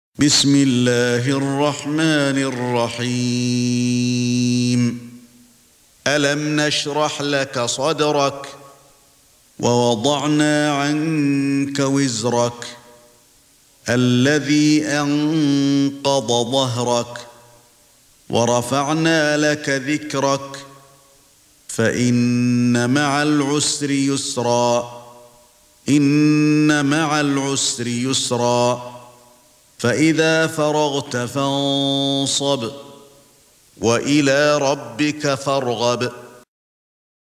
سورة الشرح ( برواية قالون ) > مصحف الشيخ علي الحذيفي ( رواية قالون ) > المصحف - تلاوات الحرمين